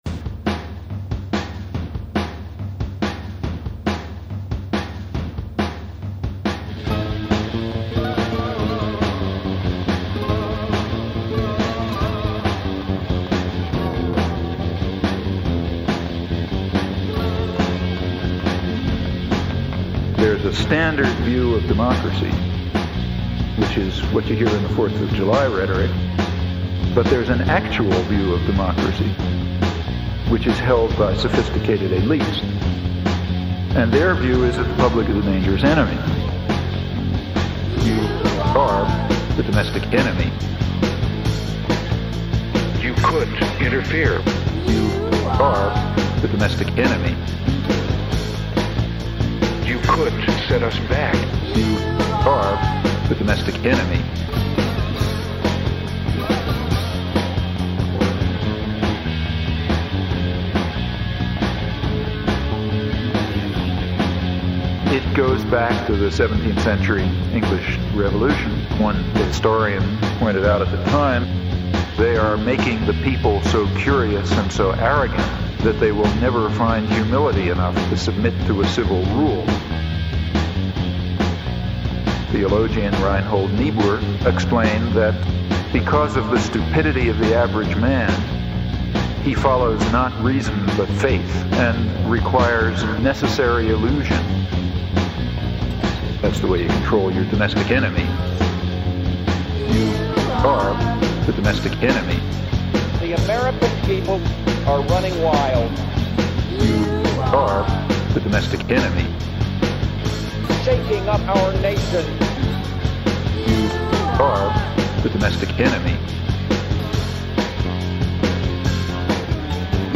Radical f*cking philosophy set to punk f*cking rock!